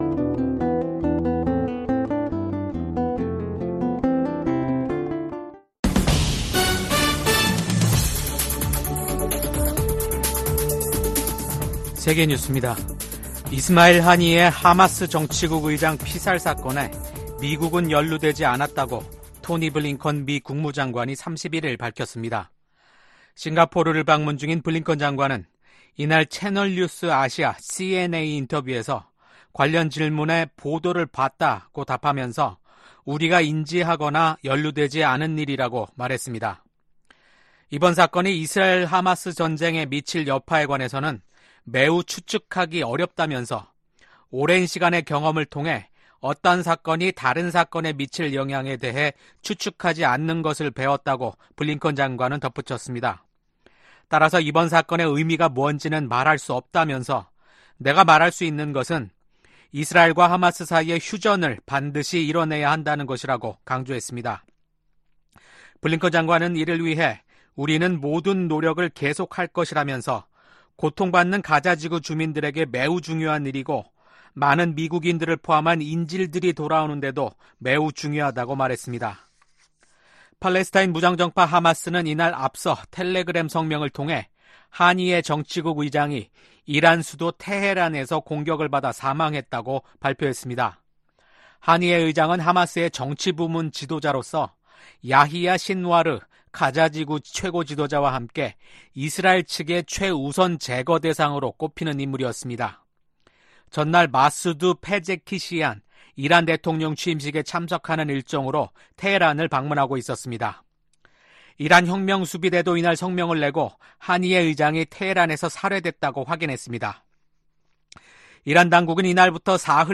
VOA 한국어 아침 뉴스 프로그램 '워싱턴 뉴스 광장' 2024년 8월 1일 방송입니다. 중국과 러시아가 북한에 대한 영향력을 놓고 서로 경쟁하고 있다고 미국 국무부 부장관이 평가했습니다. 최근 몇 년간 중국, 러시아, 이란, 북한간 협력이 심화돼 미국이 냉전 종식 이후 가장 심각한 위협에 직면했다고 미국 의회 산하 기구가 평가했습니다.